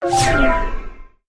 Звук улетающего послания